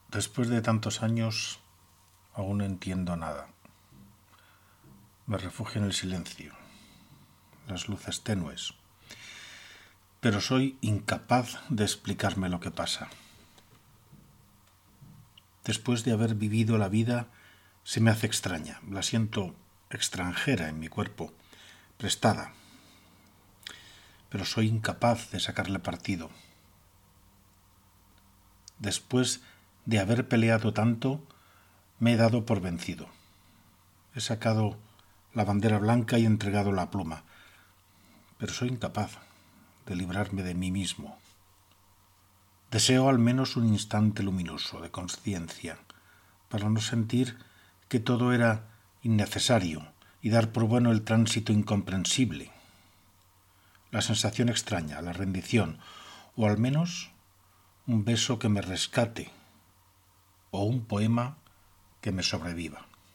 Audio recitado por mi con la poesía: Pasados los años